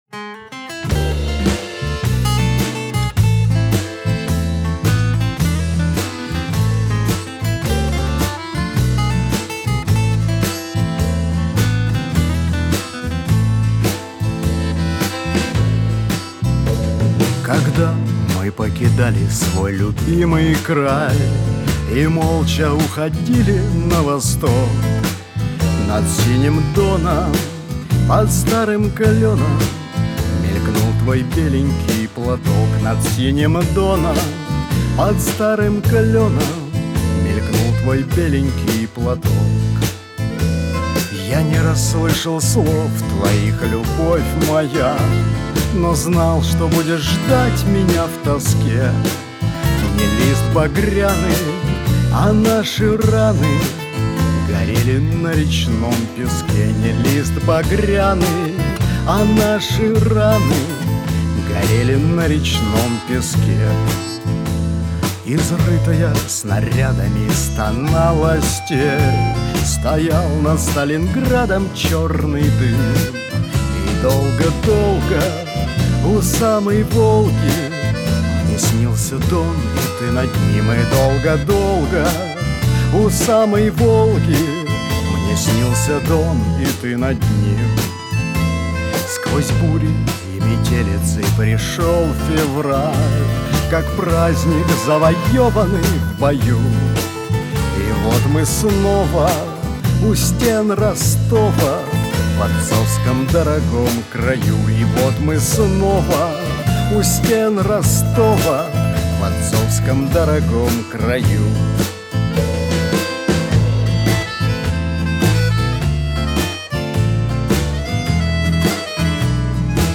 В стиле "ретро-шансон"
вокалист
гитарист